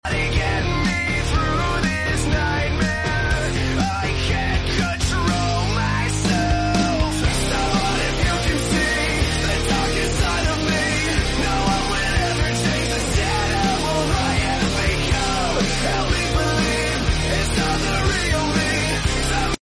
T Rex Vs Spinosaurus Sound Effects Free Download